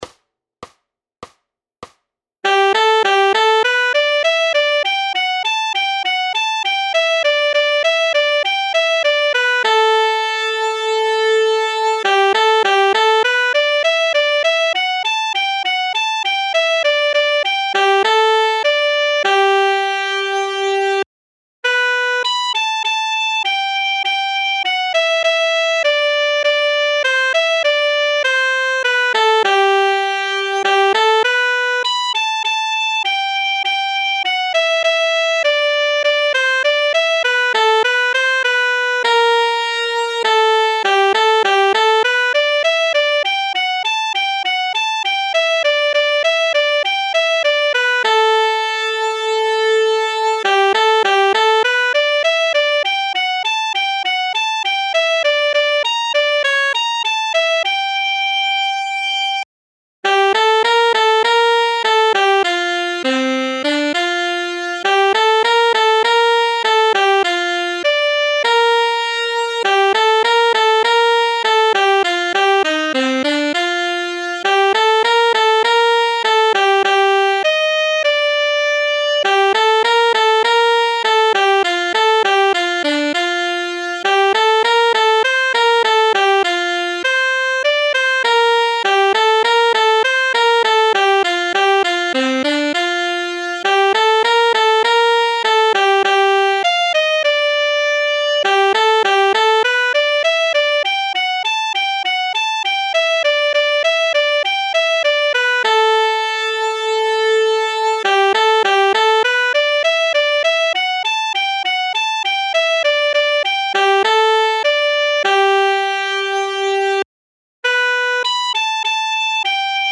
Noty na altsaxofon.
Aranžmá Noty na altsaxofon
Hudební žánr Klasický